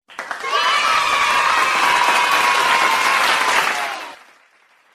Sound effect tepuk tangan anak kecil
Kategori: Suara meme
Suara ini memberikan nuansa ceria dan bisa digunakan dalam berbagai proyek...
sound-effect-tepuk-tangan-anak-kecil-id-www_tiengdong_com.mp3